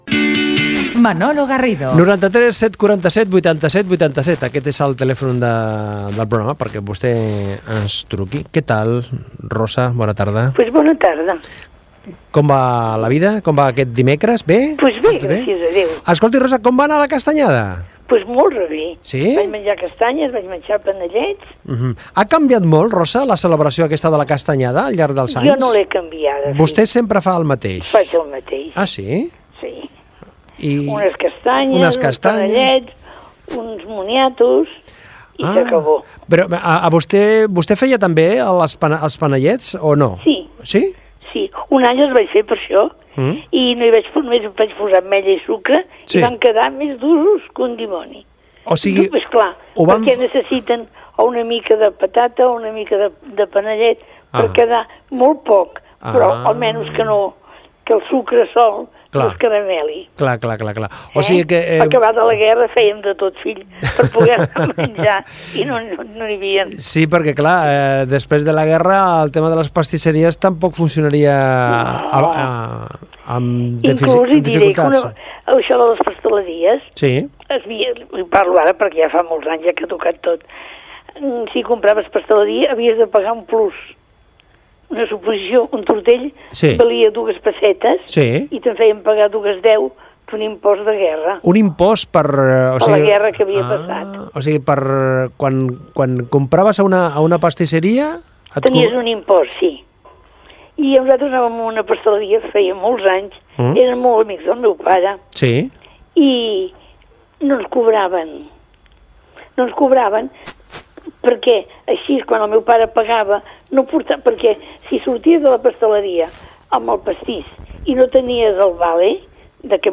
Conversa telefònica amb una oient de més de 90 anys sobre els panellets i les castanyes. Indicatiu del programa.
Entreteniment